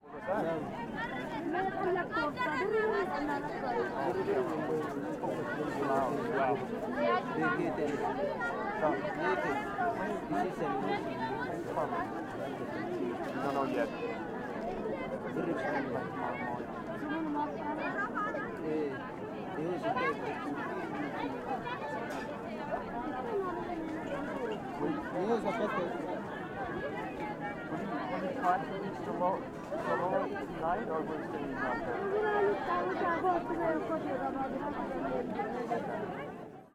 DAYTIME,-ETIOPIAN-FOOD-MARKED,-PEOPLE-BARGAINING,-CHATTING,-WALLA-MARKET_MOTHERLAND-ETHIOPIA_05.oga